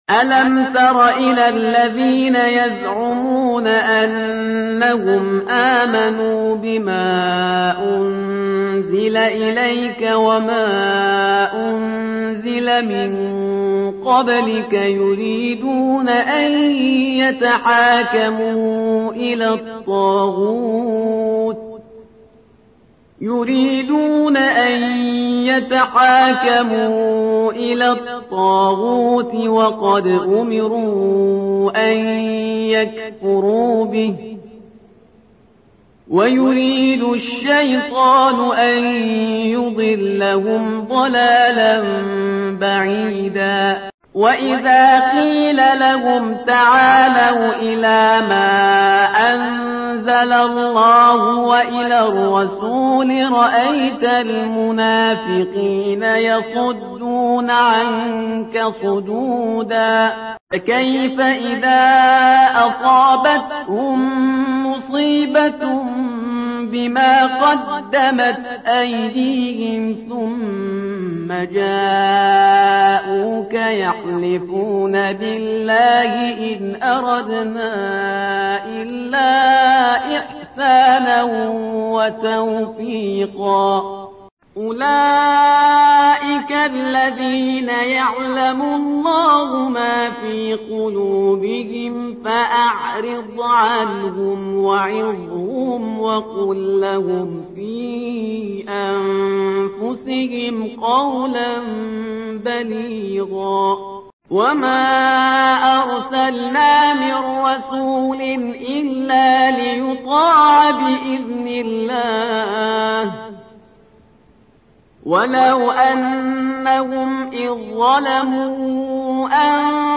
ترتیل صفحه ۸۸ سوره نساء با قرائت استاد پرهیزگار(جزء پنجم)
ترتیل سوره(نساء)